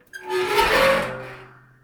Creaking metal
metal_creak7.wav